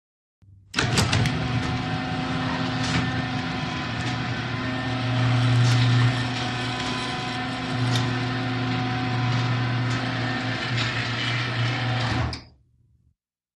DOORS VARIOUS GARAGE DOORS: Garage door, electric, open & close.